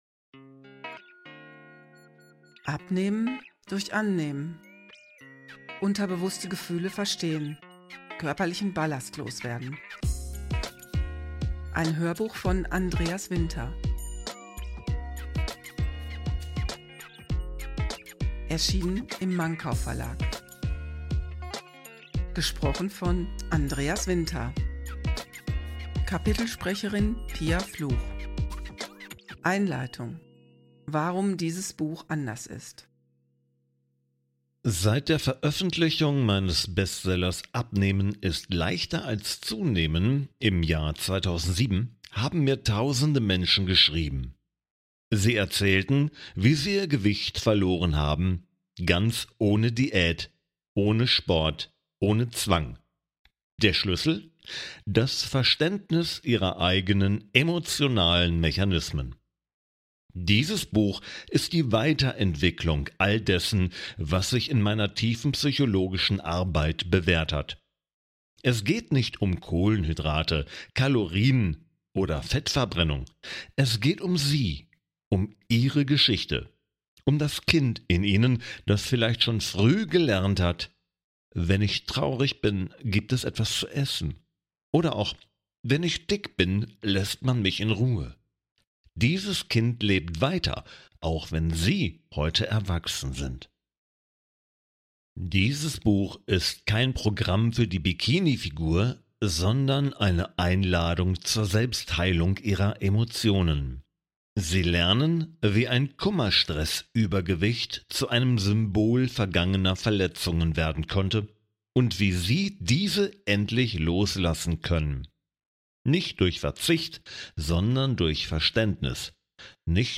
Hörprobe zum Hörbuch